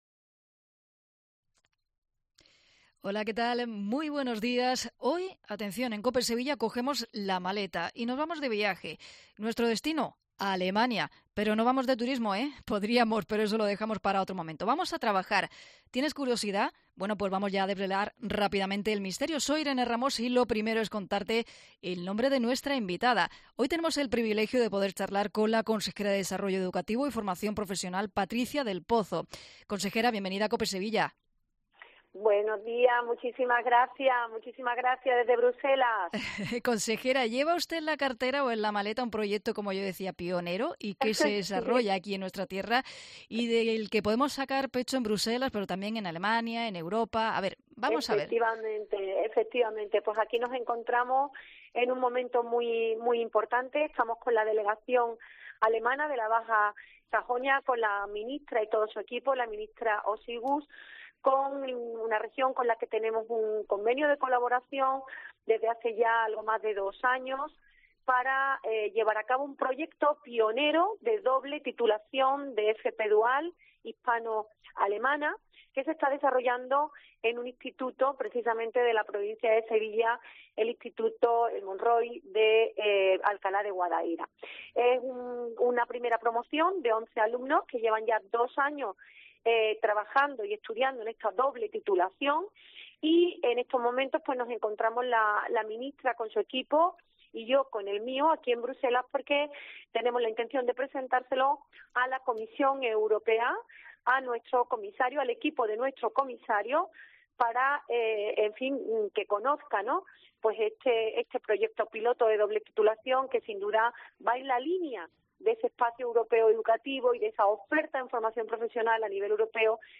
Lo ha explicado en COPE Sevilla Patricia del Pozo, quien señala que la formación dura tres años y “ya de entrada te permite trabajar en dos países porque tu titulación está reconocida por el Gobierno en Alemania” y el de España, algo que sin duda es una gran ventaja, “una ventaja importantísima”,una FP DUAL que “te conduce directamente al empleo”.